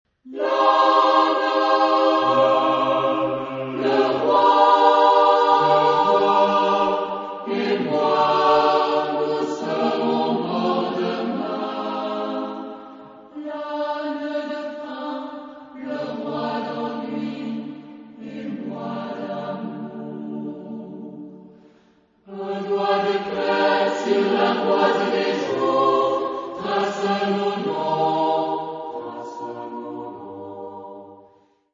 Genre-Style-Forme : Profane ; Chanson ; Poème
Caractère de la pièce : humoristique
Type de choeur : SATB  (4 voix mixtes )
Tonalité : sol mineur
Consultable sous : Populaire Francophone Acappella